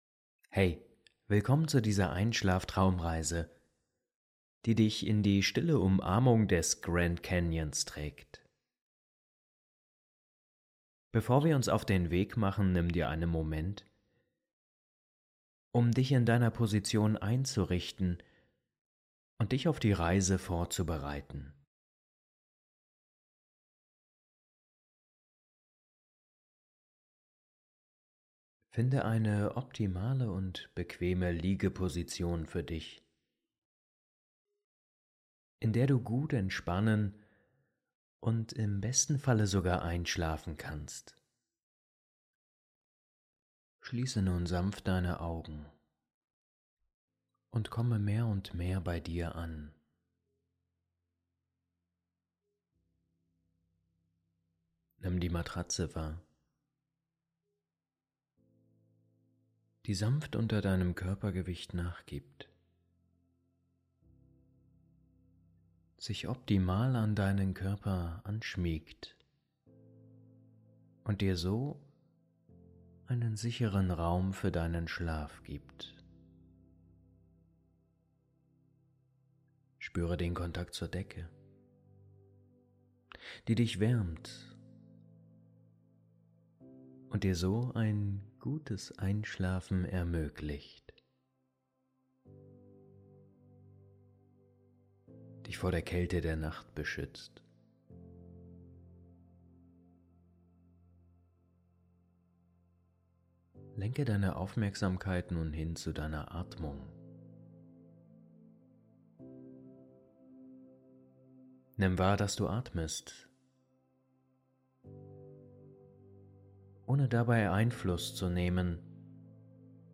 Die ruhige und langsame Erzählweise hilft dir, die Gedanken loszulassen und entspannt einzuschlafen.